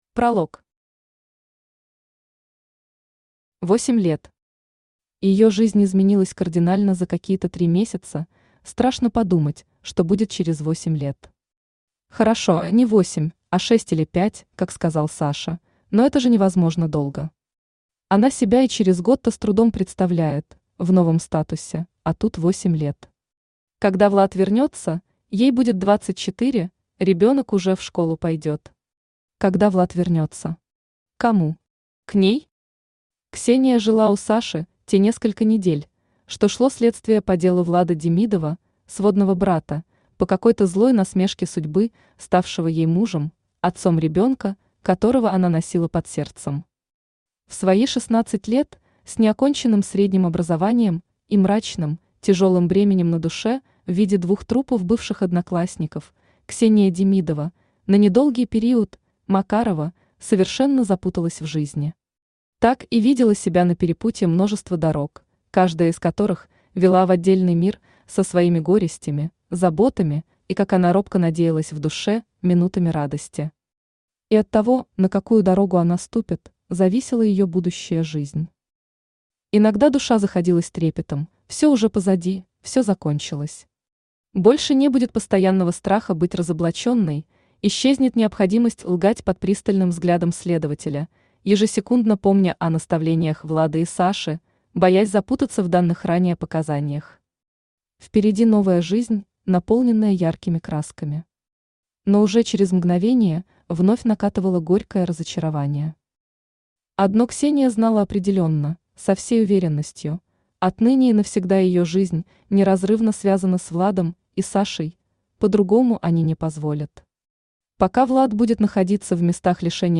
Аудиокнига Тот, для кого живу | Библиотека аудиокниг
Aудиокнига Тот, для кого живу Автор Александра Ронис Читает аудиокнигу Авточтец ЛитРес.